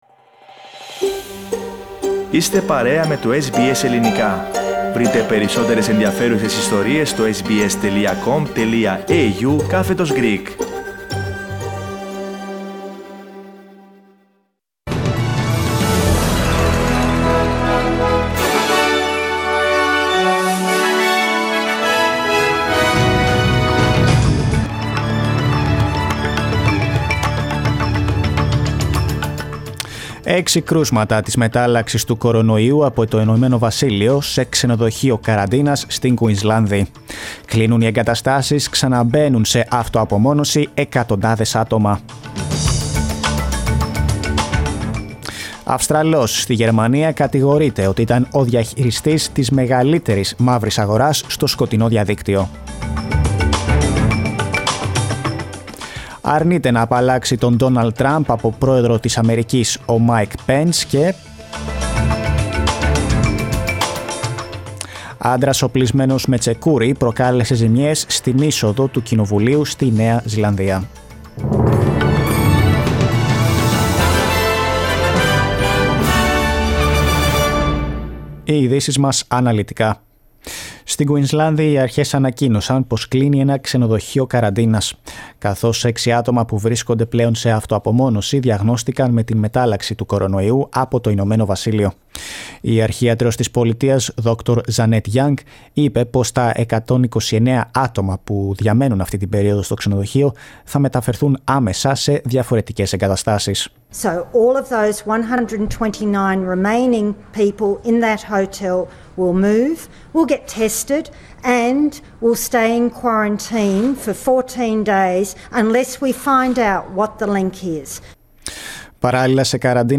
News from Australia, Greece, Cyprus and the world in the News Bulletin of Wednesday 13 of January 2021